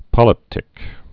(pŏlĭp-tĭk)